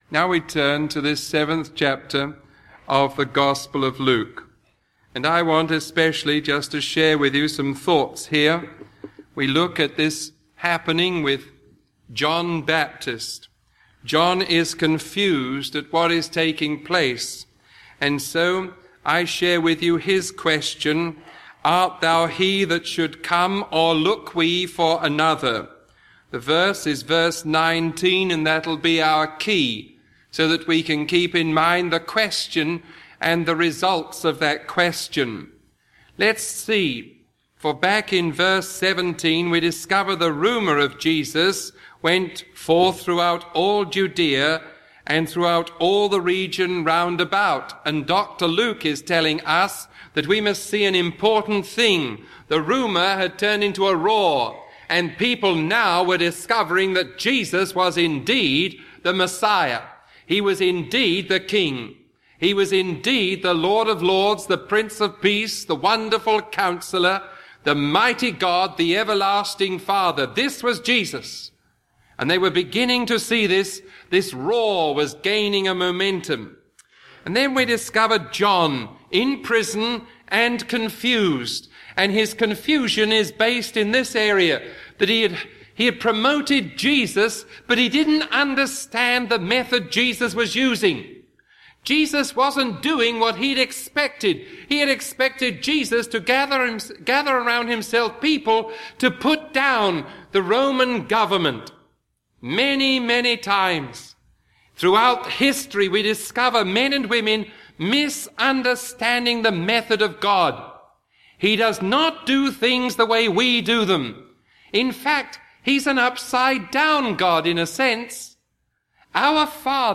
Sermon 0046A recorded on April 22